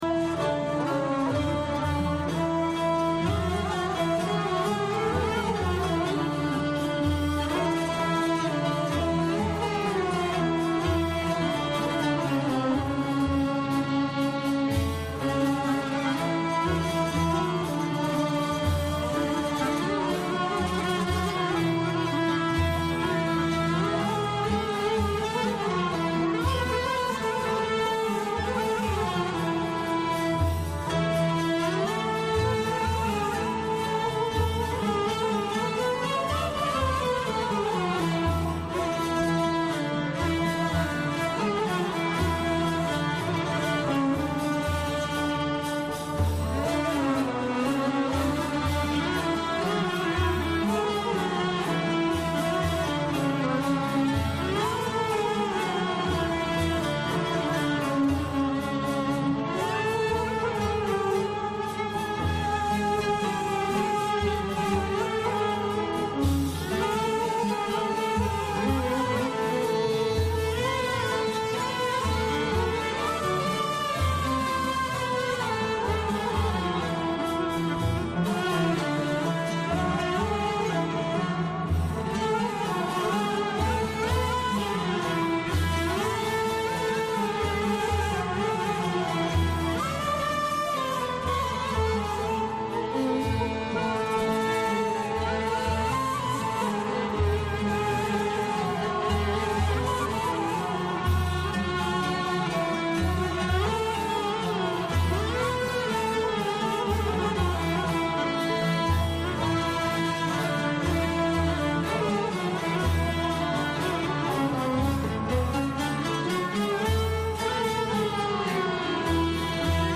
Dil Verdiğin Ol Çeşm-i Siyeh-meste İşittim - Zekai Dede - Hüzzam | DîvânMakam